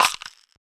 shaker 1.wav